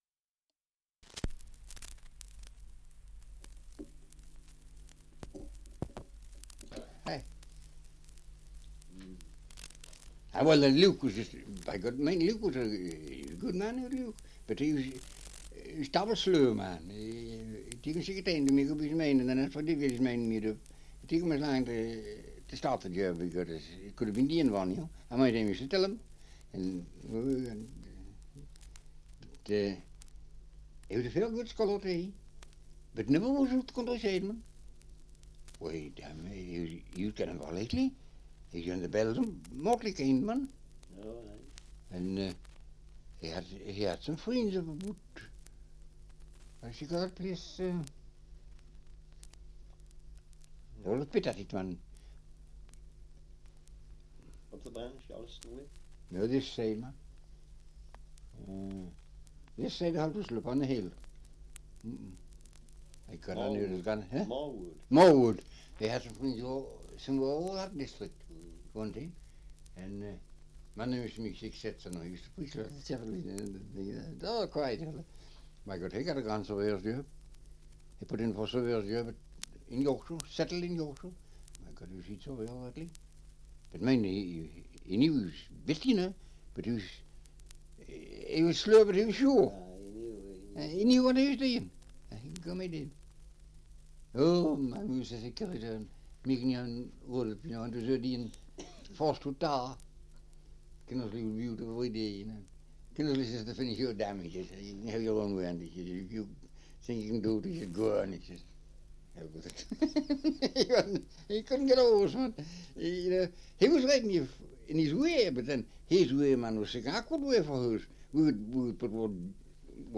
2 - Survey of English Dialects recording in Elsdon, Northumberland. Survey of English Dialects recording in Wark, Northumberland
78 r.p.m., cellulose nitrate on aluminium